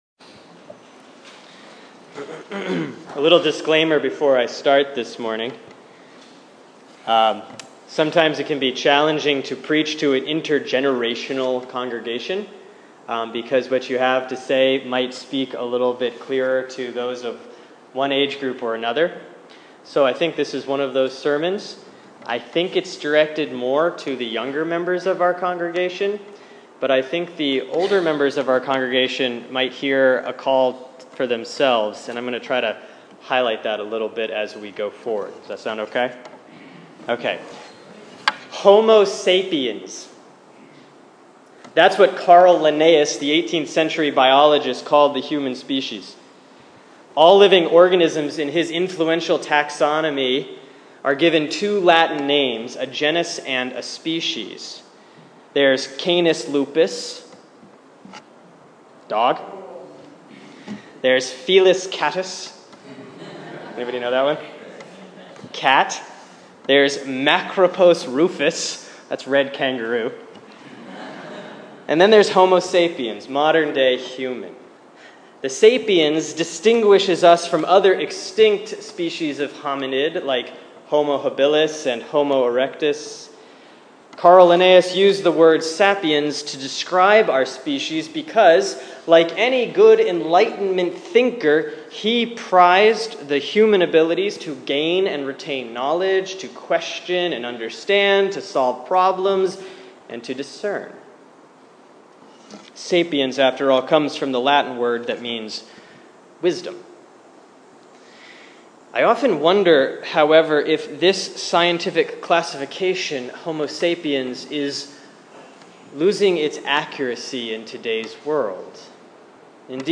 Sermon for Sunday, August 16, 2015 || Proper 15B || Proverbs 9:1-6